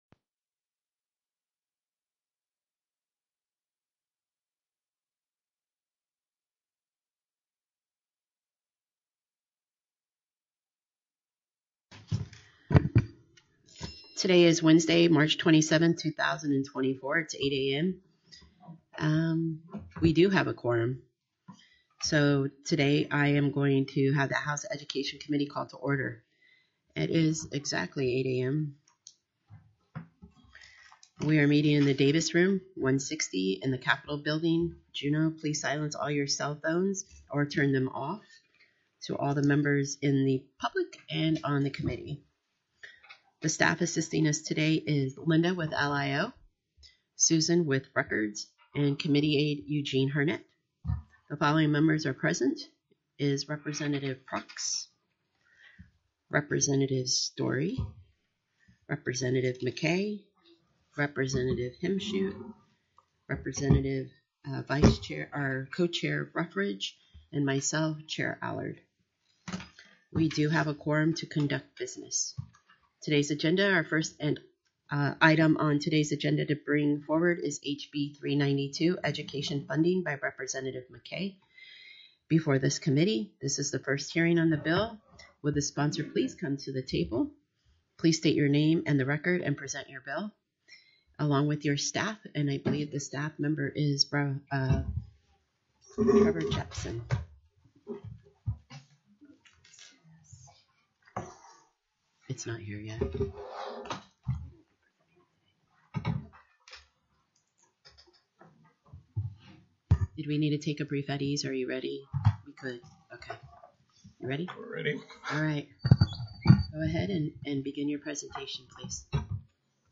03/27/2024 08:00 AM House EDUCATION
The audio recordings are captured by our records offices as the official record of the meeting and will have more accurate timestamps.
Invited & Public Testimony